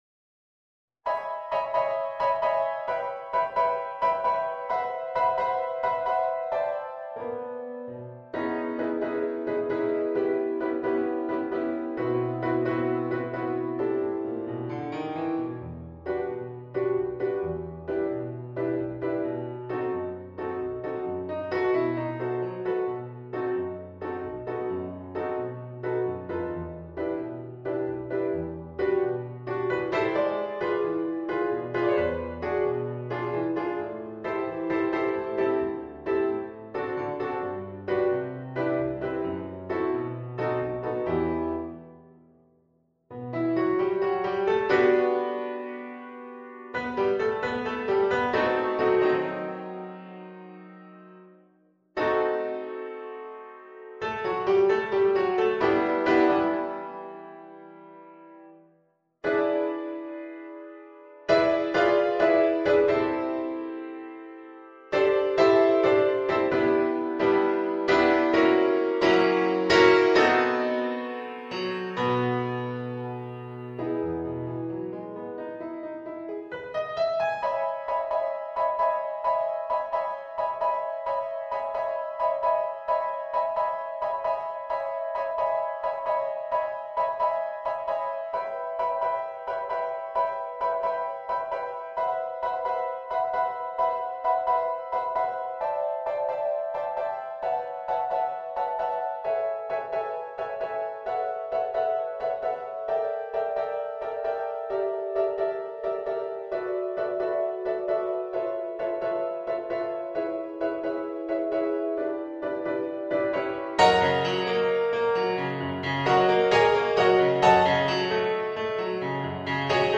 bassoon, piano
(Audio generated by Sibelius)